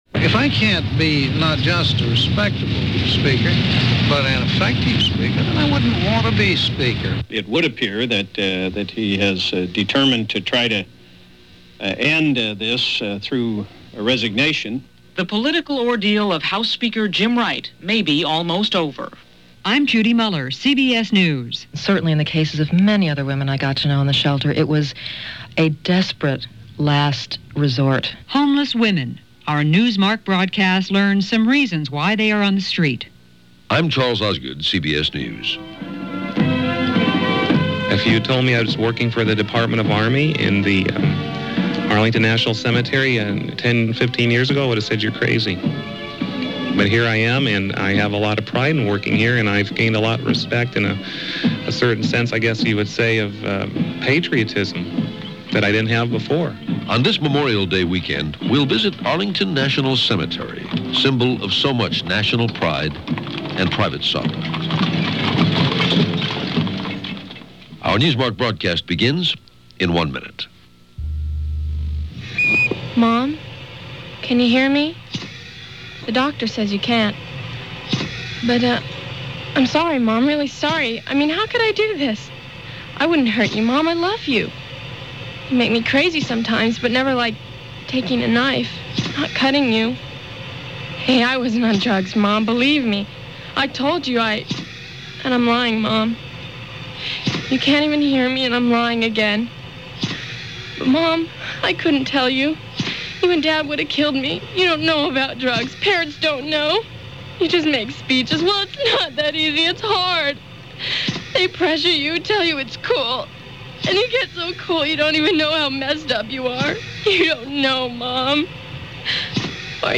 Ethics, Morals And Scandals - Welcome To Capitol Hill - It's May 28, 1989 - CBS Radio Program Newsmark + news.